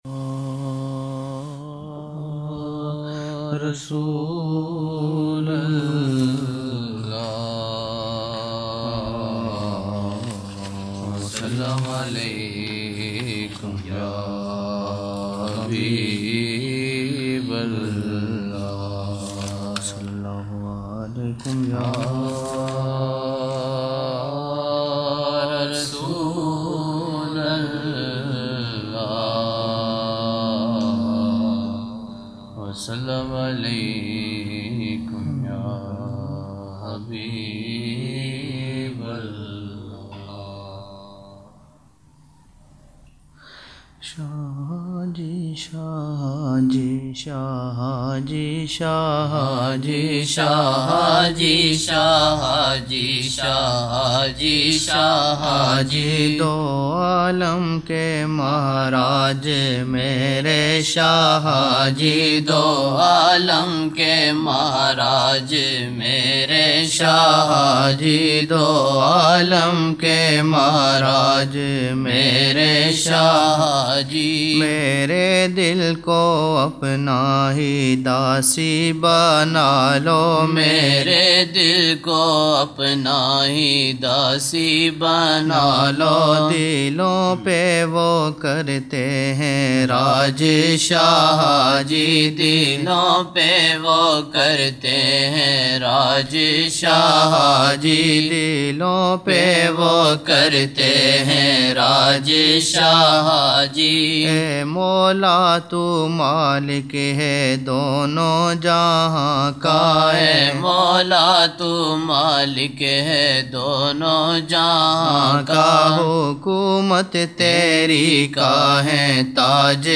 16 November 1999 - Zohar mehfil (8 Shaban 1420)
Naat shareef
Chand naatia ashaar